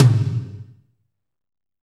TOM F RHM1HR.wav